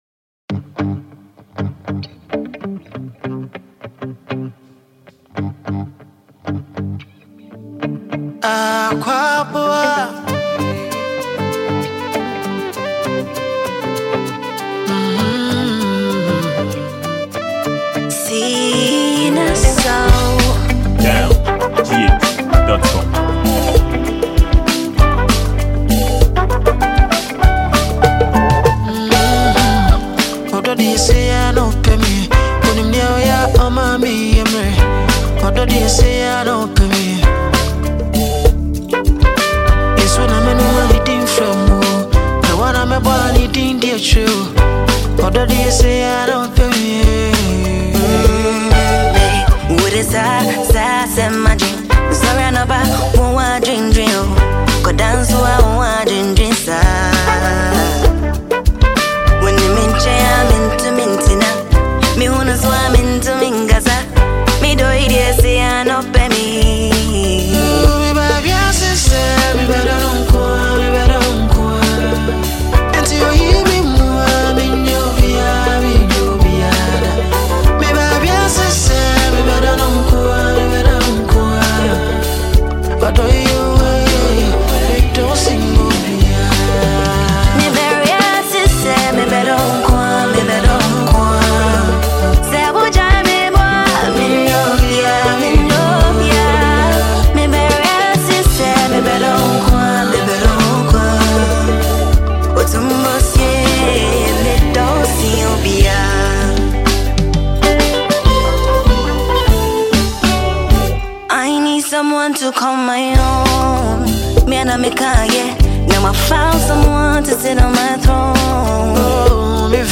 Ghanaian highlife songwriter and recording artist